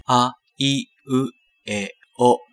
ひとまず私の声をサンプルにしました。がらがら声で必ずしもいいサンプルではないのですが、ご容赦ください。
人間の声（あ行）
aiueo.au